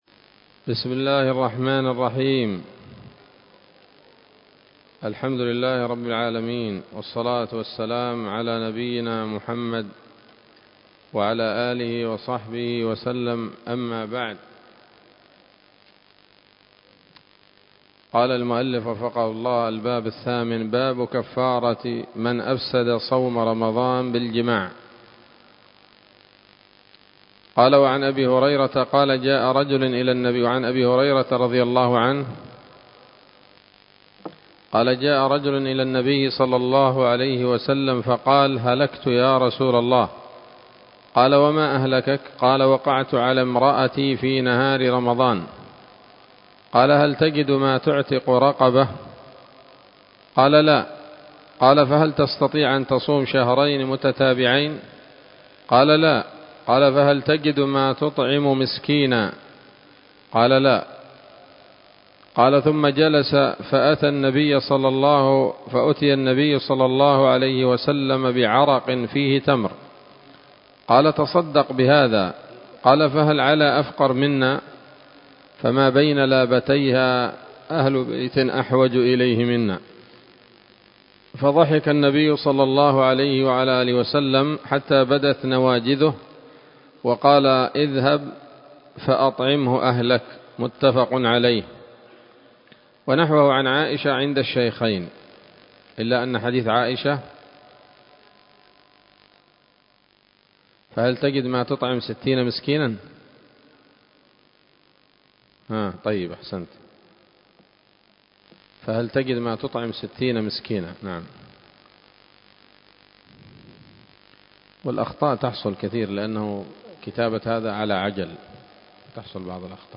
الدرس العاشر من كتاب الصيام من نثر الأزهار في ترتيب وتهذيب واختصار نيل الأوطار